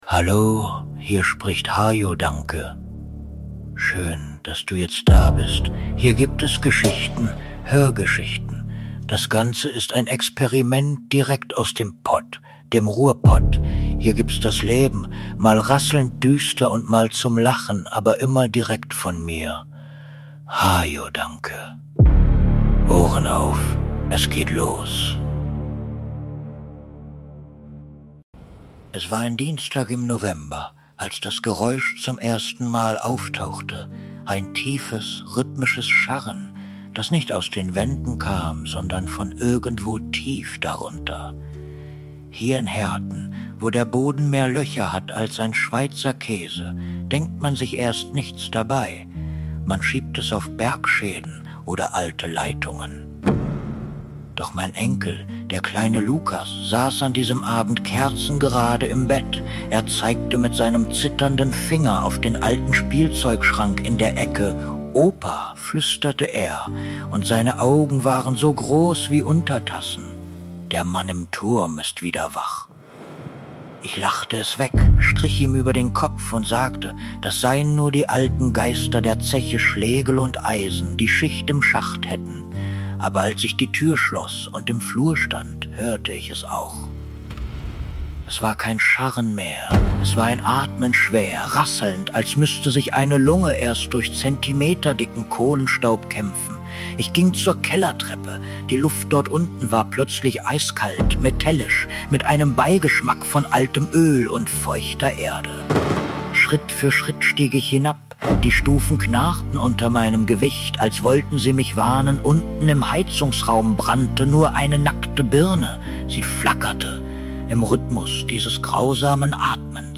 Hörgeschichten